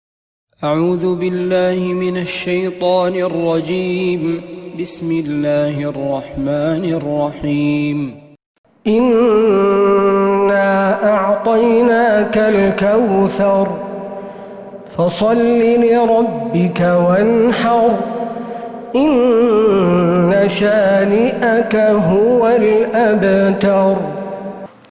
سورة الكوثر من تراويح الحرم النبوي